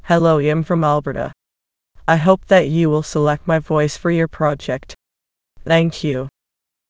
voices/VCTK_Canadian_English at main